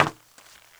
METAL 2A.WAV